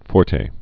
(fôrtā)